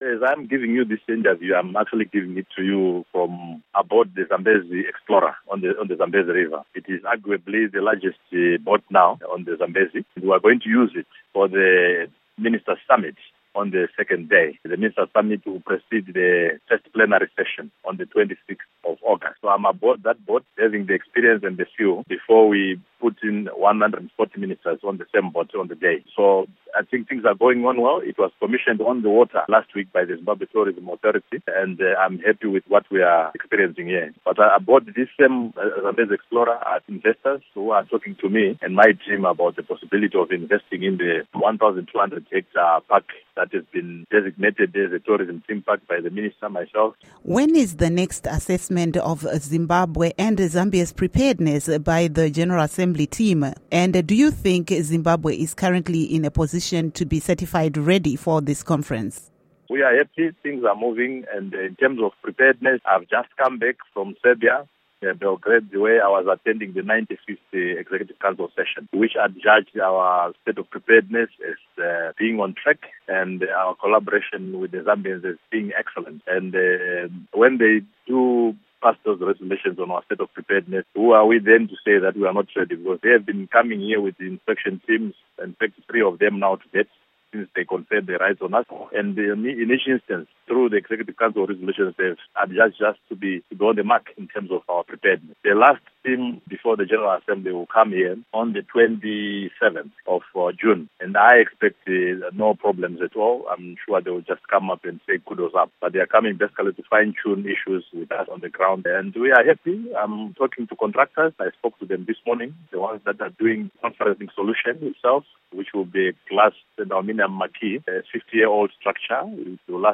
Interview with Walter Mzembi